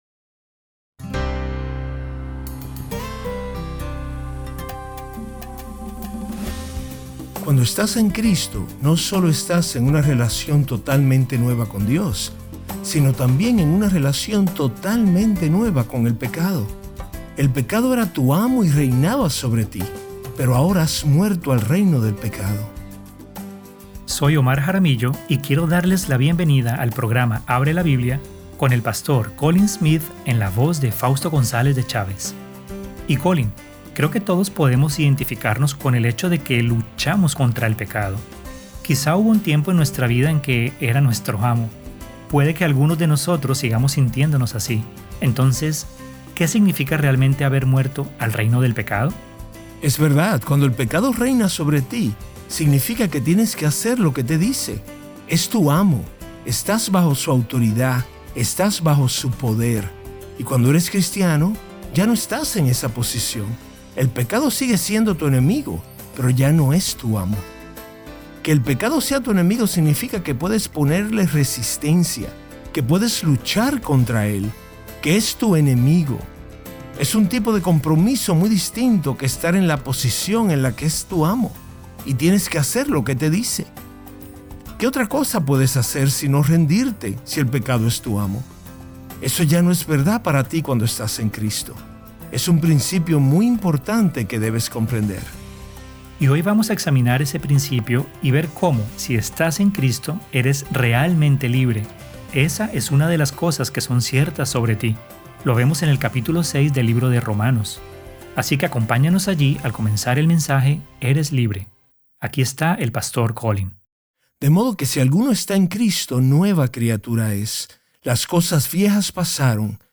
Sermón: Eres libre - Parte 1 - Abre la Biblia